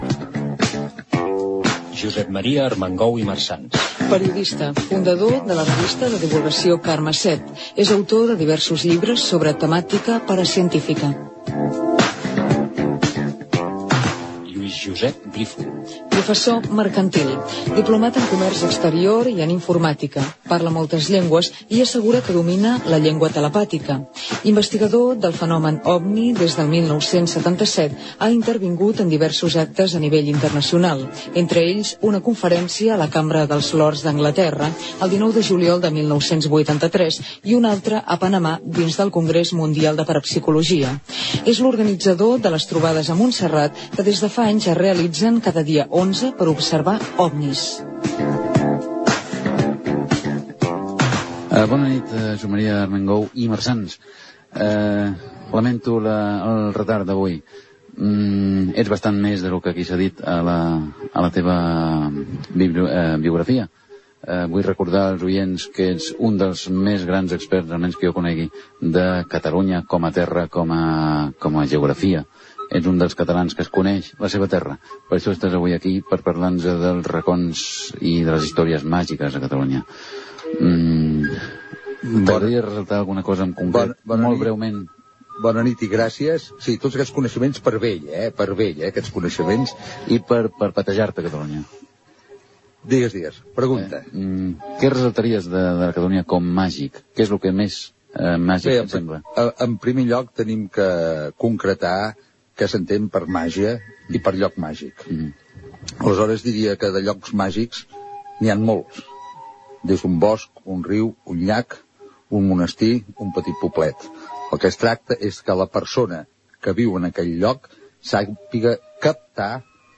Presentació dels invitats.
Divulgació